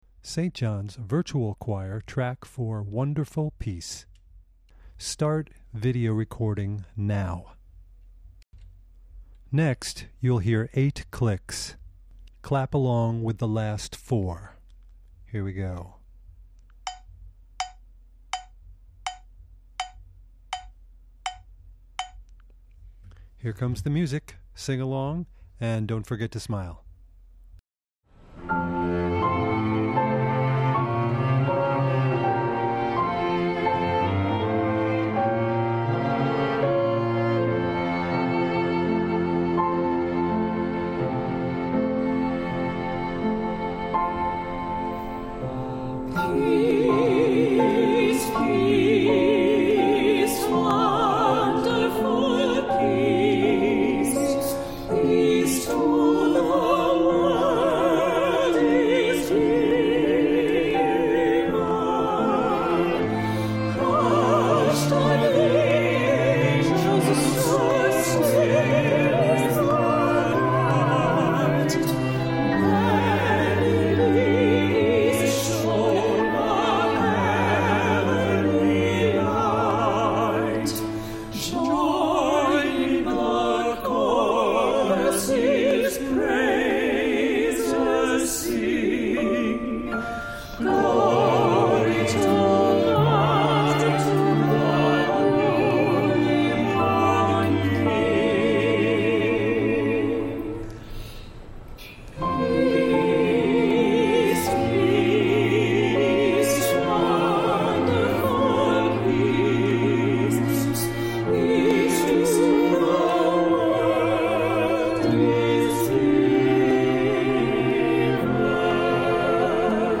Christmas 2020 - Virtual Projects:
SING-ALONG TRACK:
Full-length sing-along - MP3
WonderfulPeace_enhanced_singalong.mp3